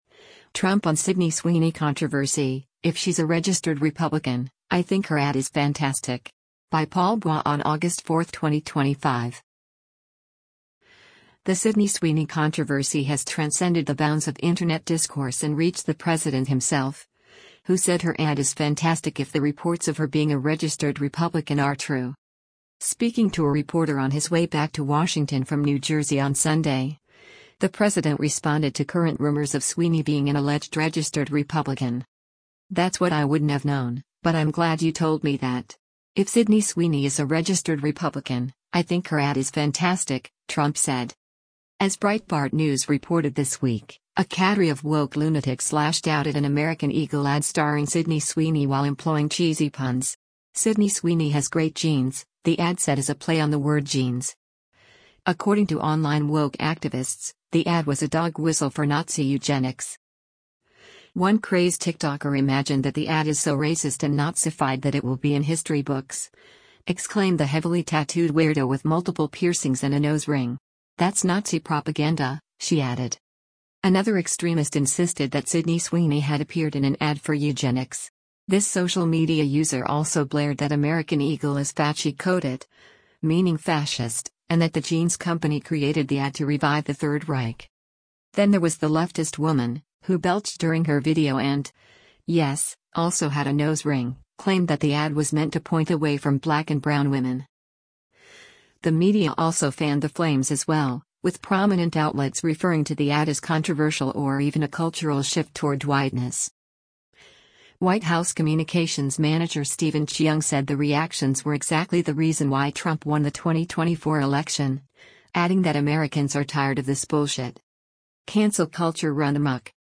Speaking to a reporter on his way back to Washington from New Jersey on Sunday, the president responded to current rumors of Sweeney being an alleged registered Republican.